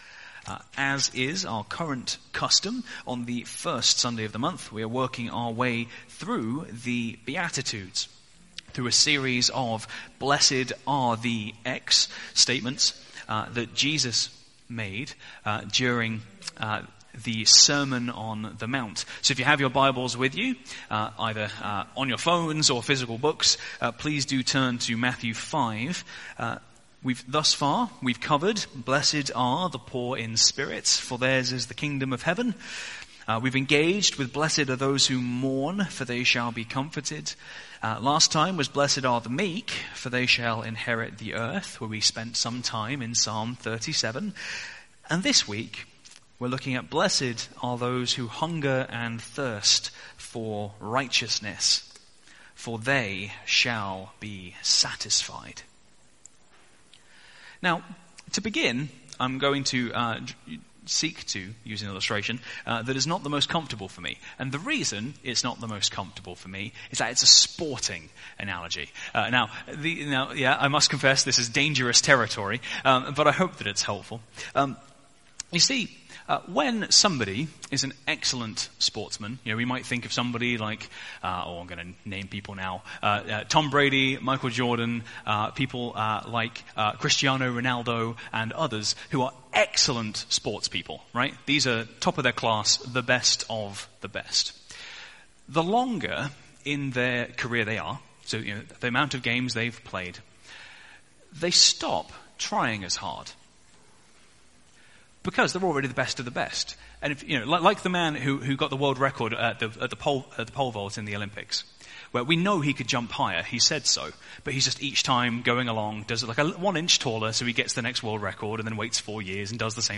Sermon Series: Beatitudes